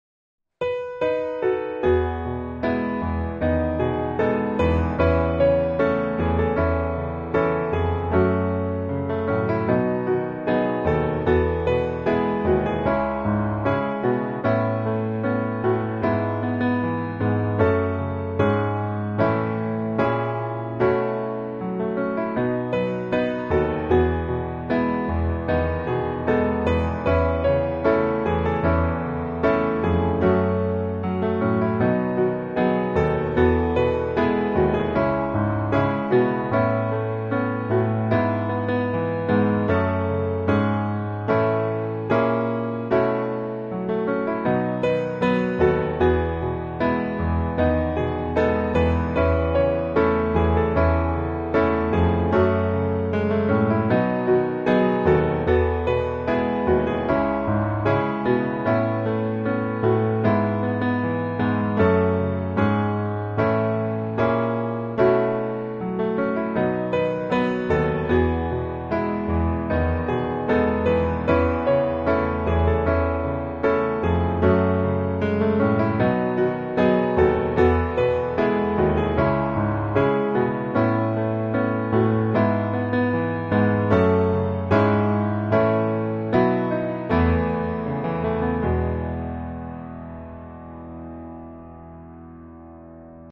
Instrumental (Guitar)
E Majeur